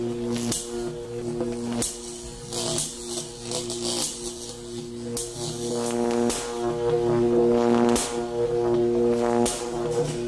LightningLoop2.wav